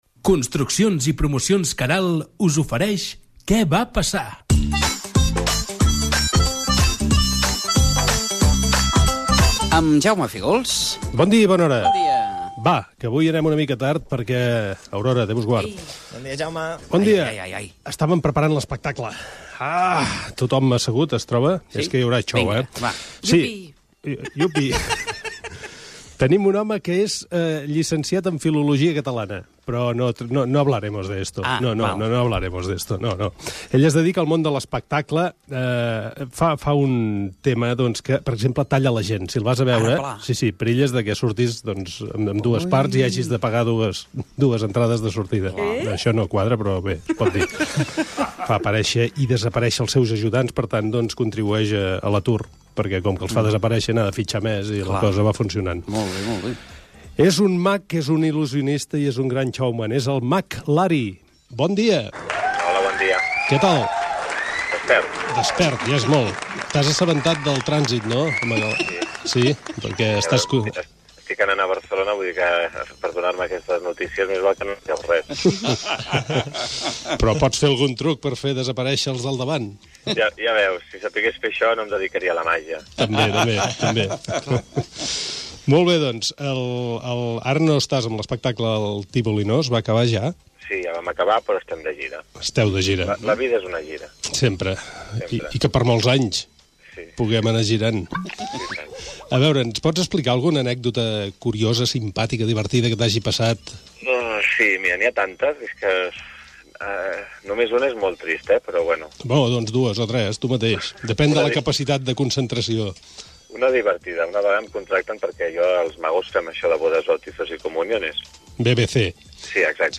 Espai "Què va passar?". Careta del programa amb publicitat i entrevista telefònica al Mag Lari (Josep Maria Lari )
Entreteniment
FM